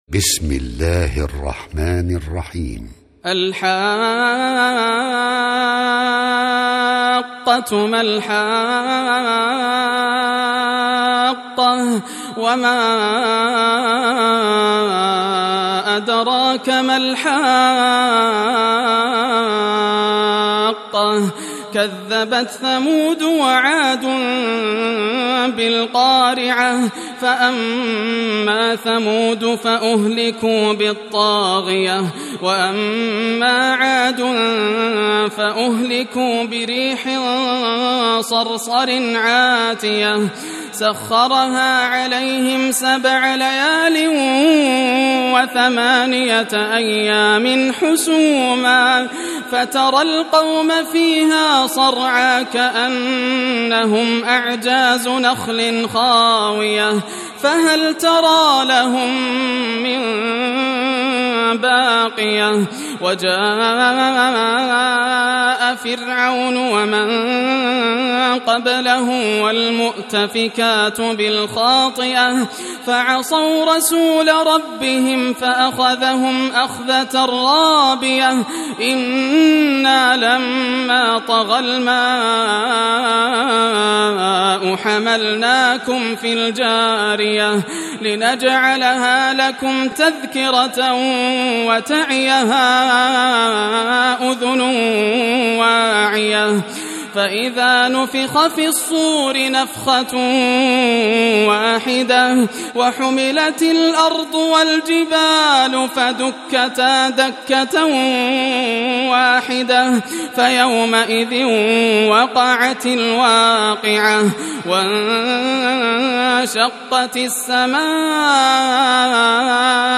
سورة الحاقة > المصحف المرتل للشيخ ياسر الدوسري > المصحف - تلاوات الحرمين